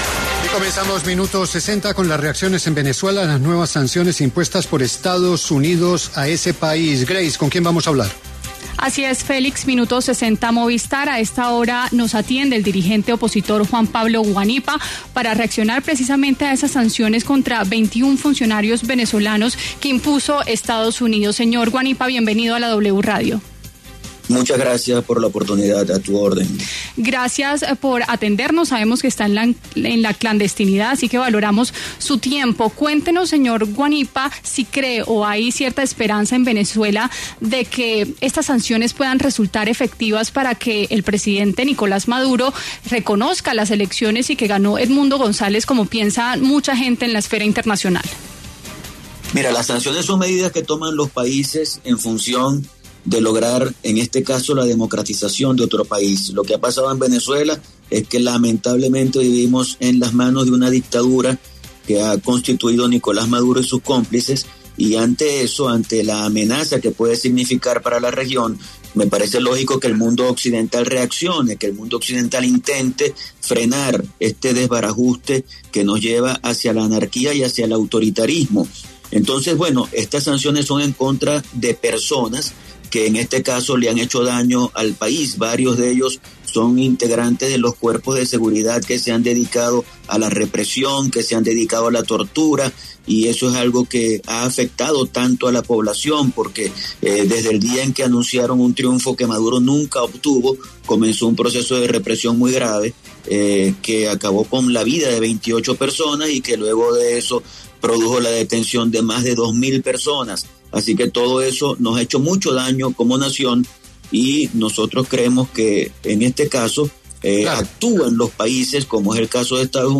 Juan Pablo Guanipa, dirigente opositor venezolano, se refirió en La W a las sanciones impuestas por Estados Unidos contra 21 funcionarios de su país.